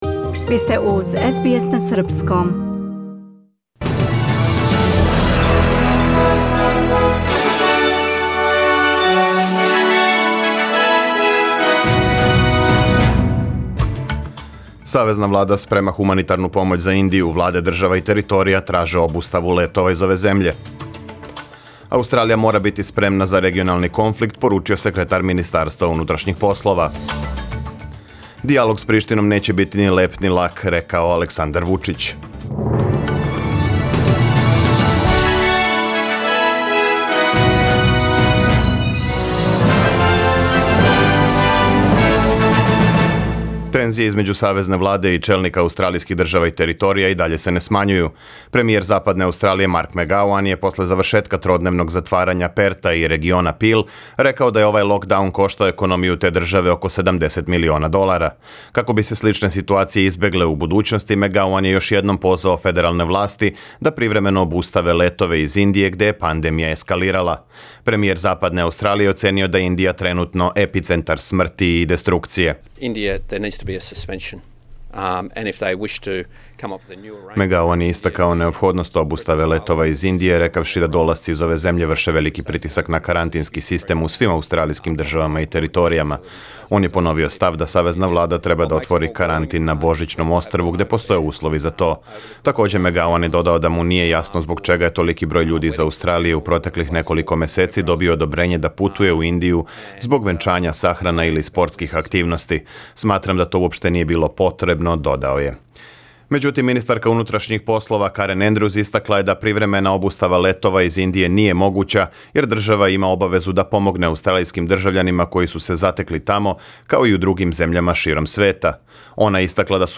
Serbian News Bulletin Source: SBS Serbian